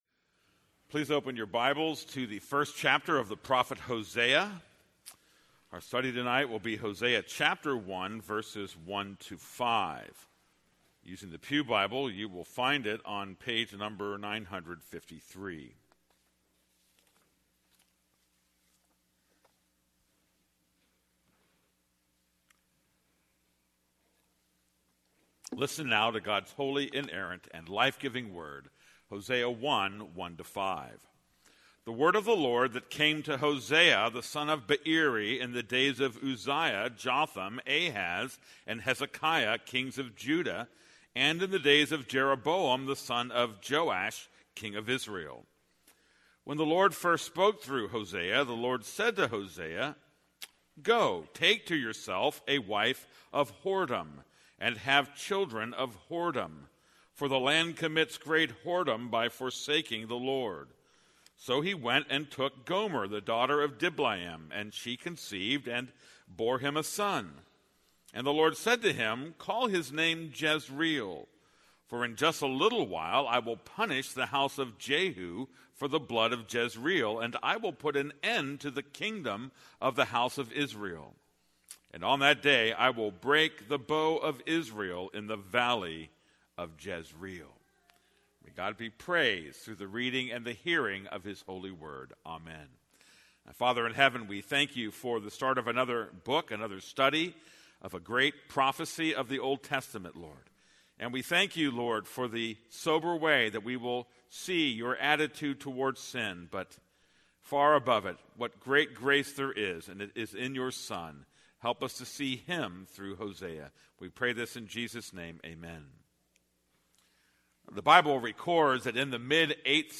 This is a sermon on Hosea 1:1-5.